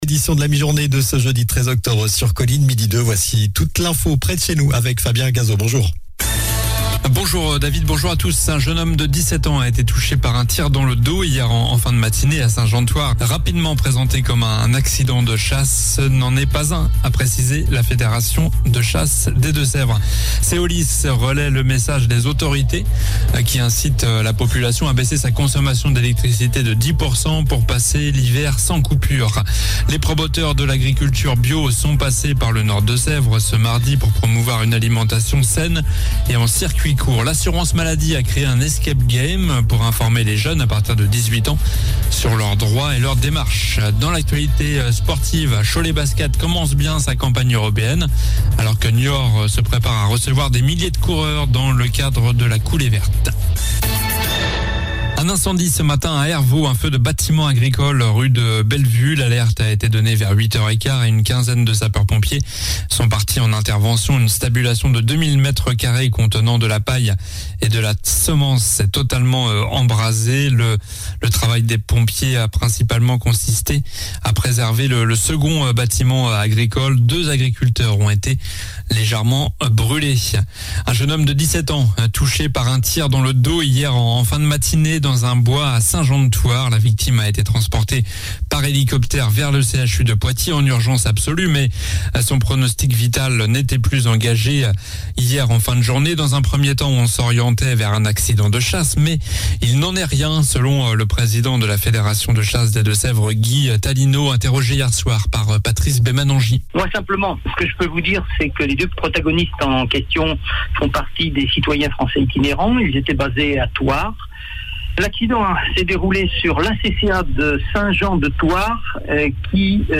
Journal du jeudi 13 octobre (midi)